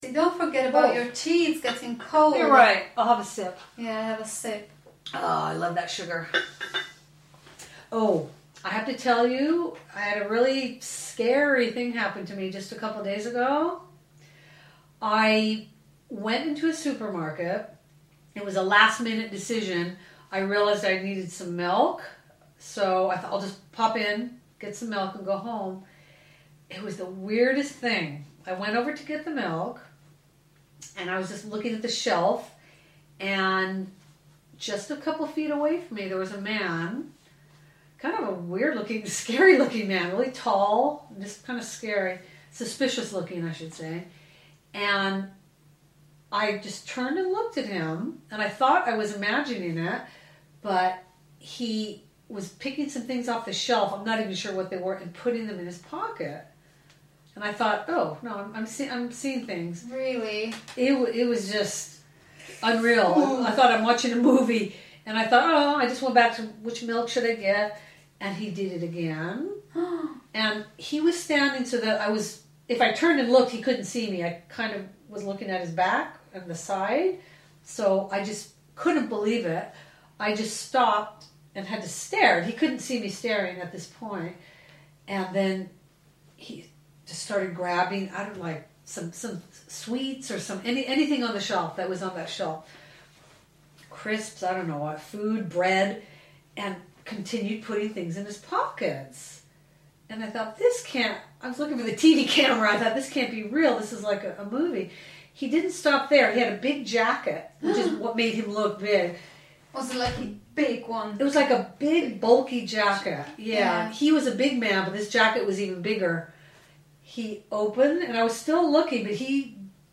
Ukázka z knihy
Polish Your Rusty English - Listening Practice 4 - unikátní a ojedinělá sbírka autentických rozhovorů anglických rodilých mluvčích.